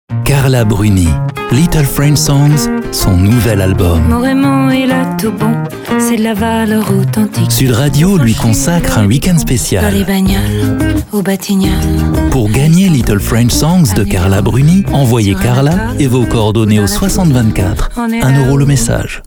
PROMO DISQUE
doux , promo , souriant